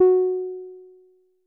BASS1 F#4.wav